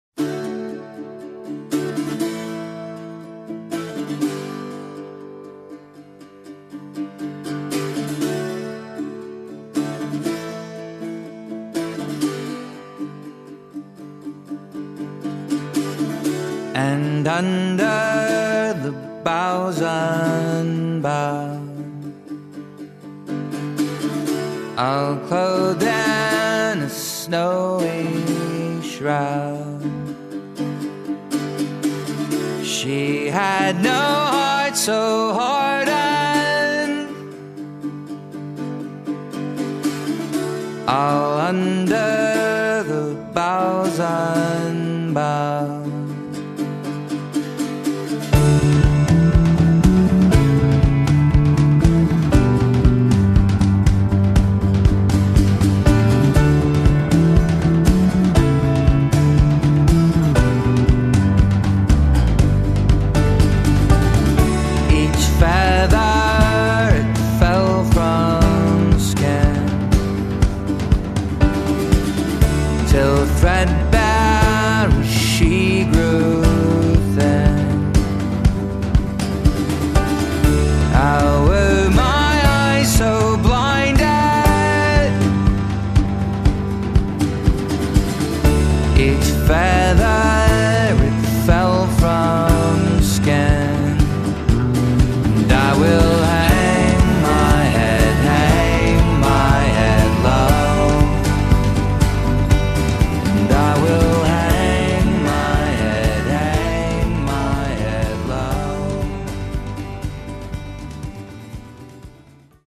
frontman, chitarra, voce e autore dei testi